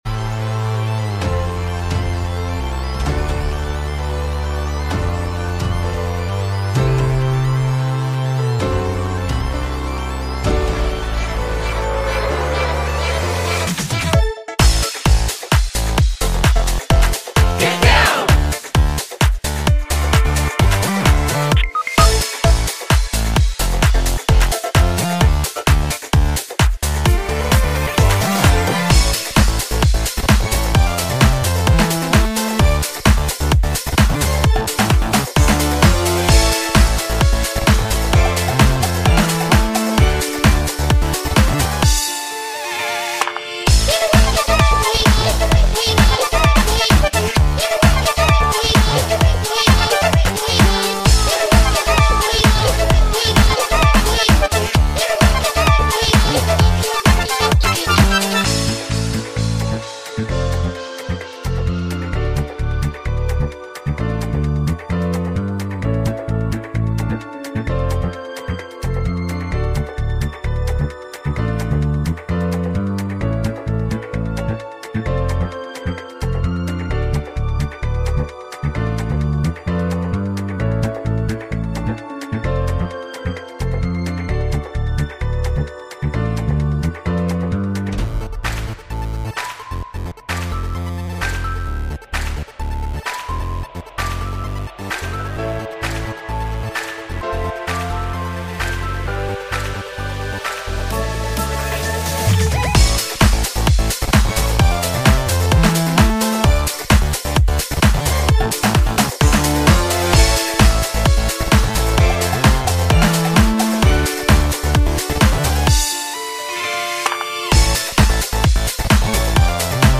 The remix I made